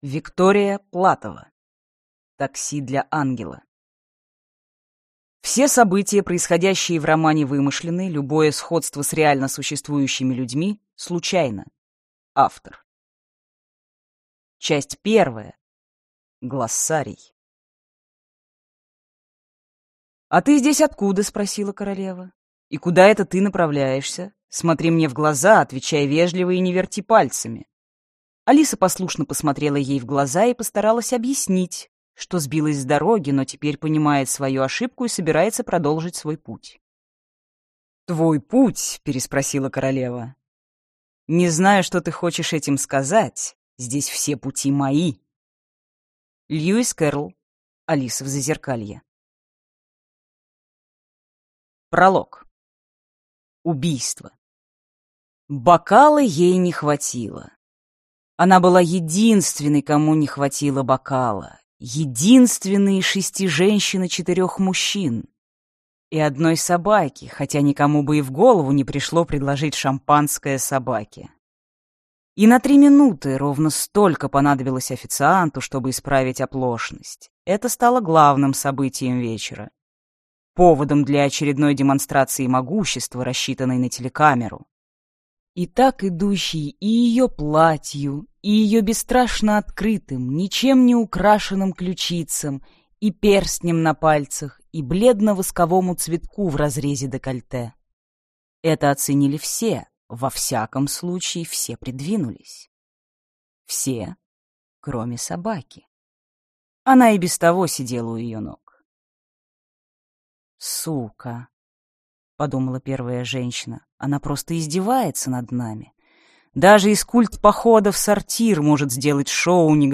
Аудиокнига Такси для ангела | Библиотека аудиокниг